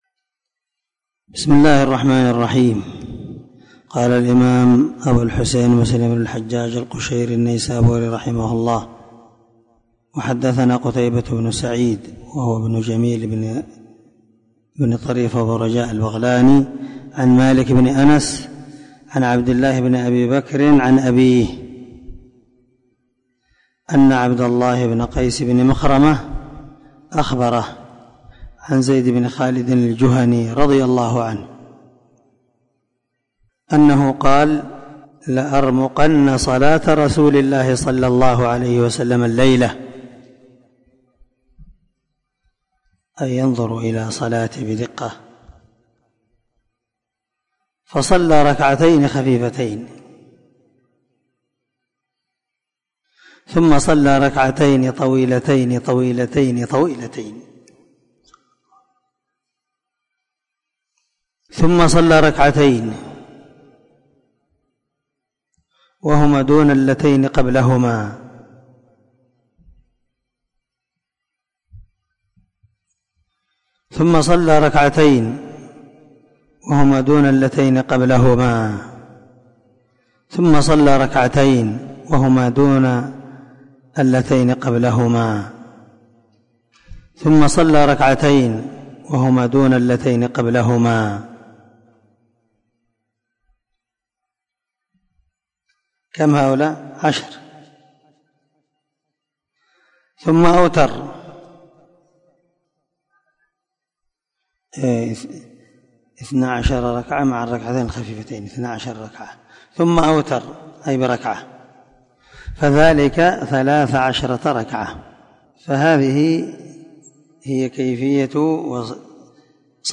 دار الحديث- المَحاوِلة- الصبيحة